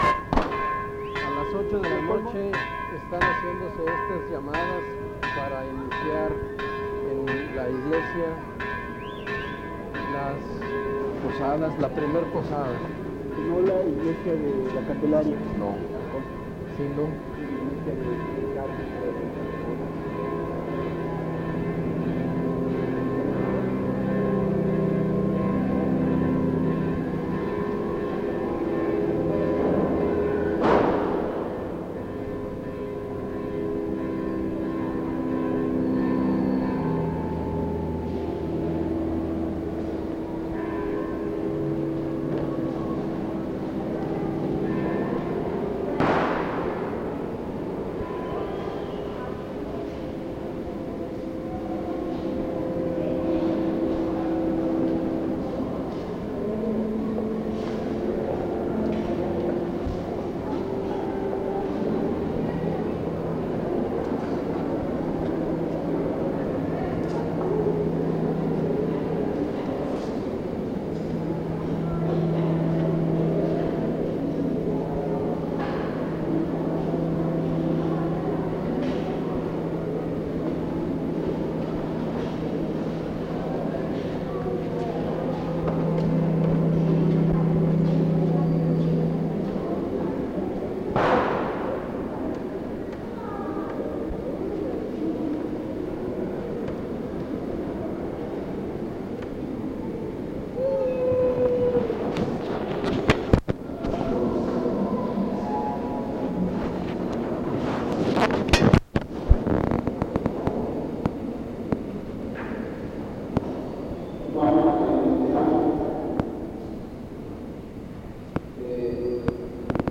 02 Posada en la Parroquia de San Cristobal